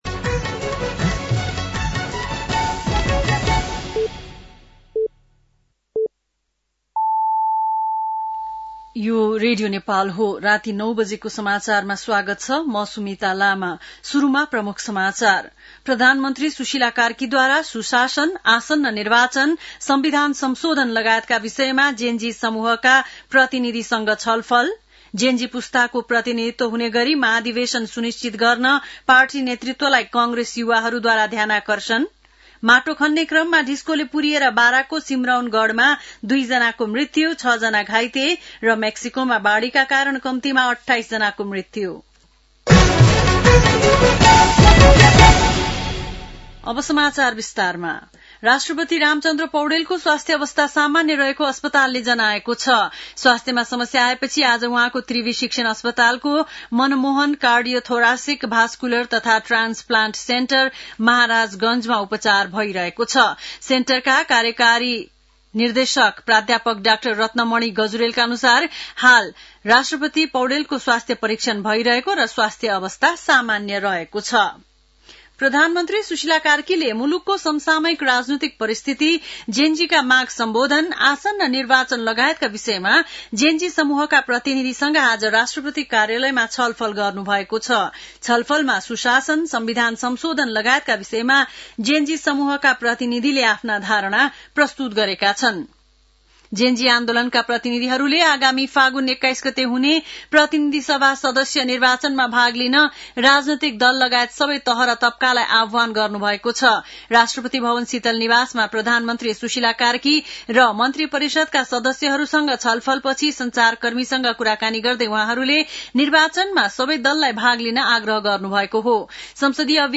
बेलुकी ९ बजेको नेपाली समाचार : २५ असोज , २०८२